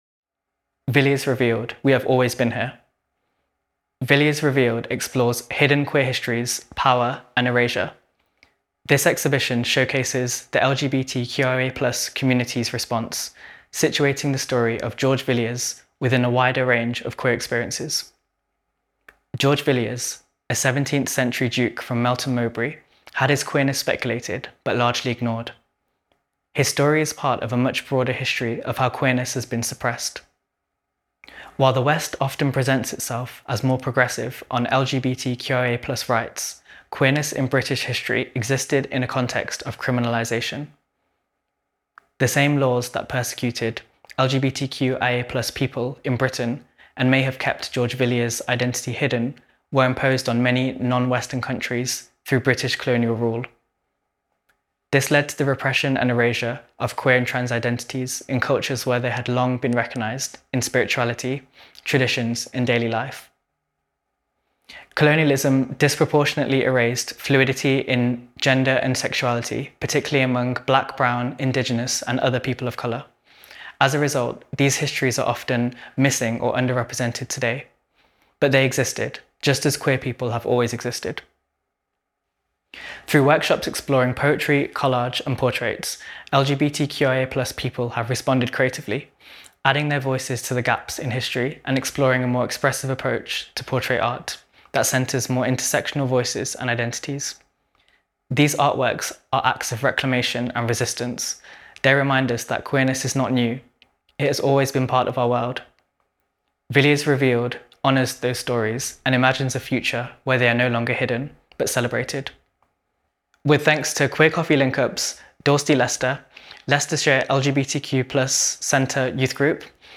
We Have Always Been Here – Audio Descriptions of Exhibition Artwork
Panels – audio